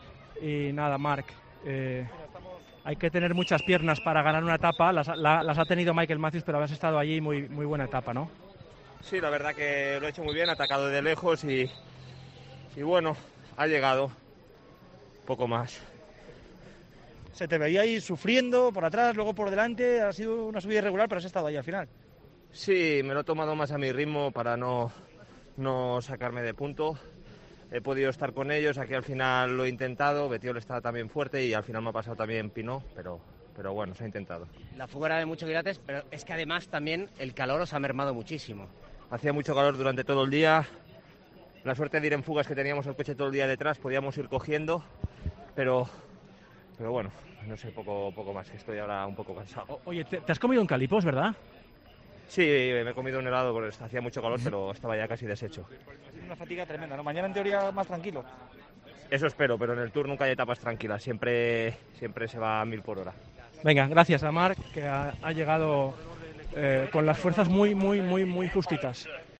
El ciclista del UAE se mostraba cansado al término de la etapa. Aseguró que "en el tour nunca hay etapas tranquilas, siempre se va a mil por hora"